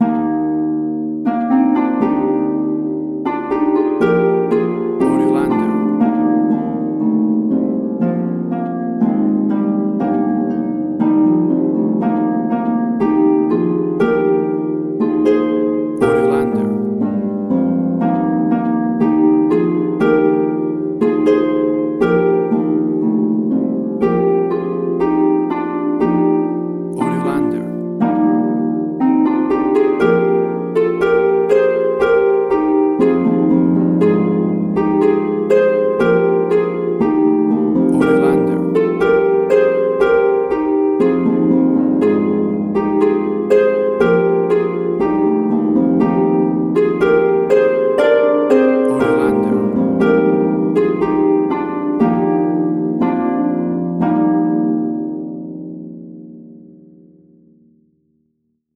A beautiful and stunning version
played on a Harp
Tempo (BPM): 120